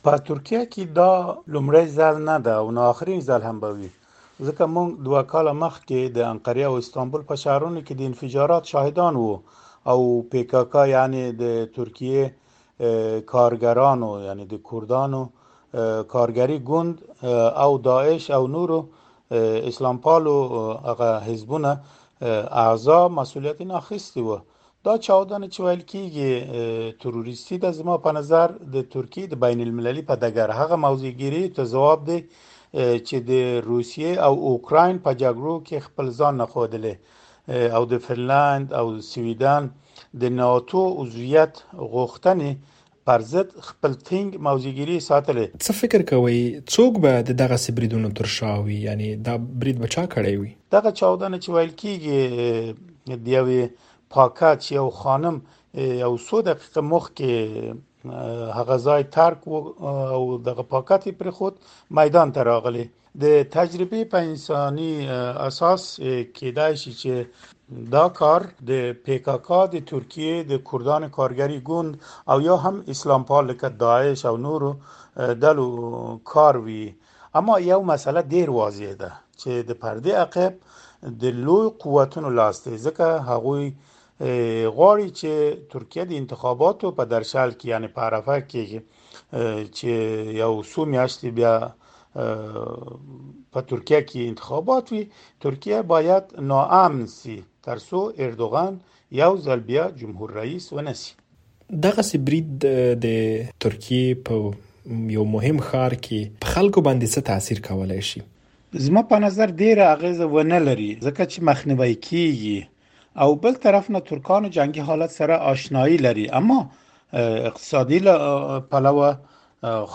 مرکه
د سردار محمد رحمن اوغلي مرکه